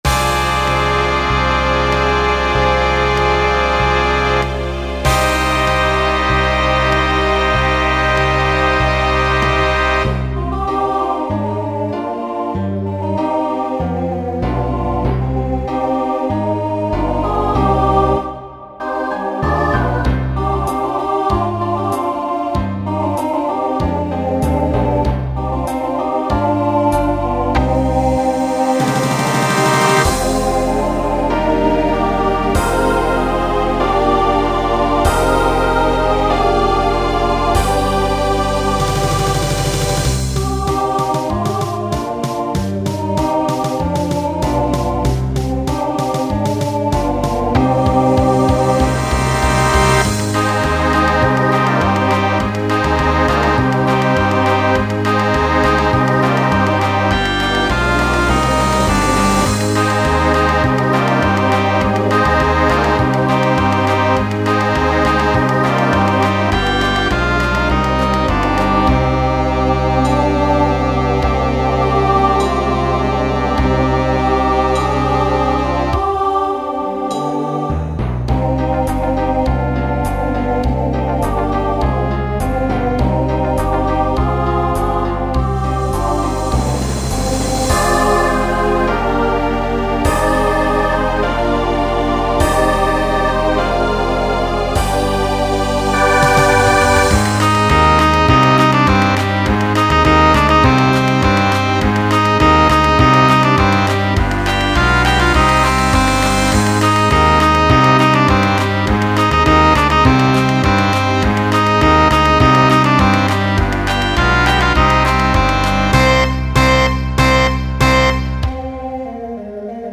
Genre Pop/Dance , Rock Instrumental combo
Voicing Mixed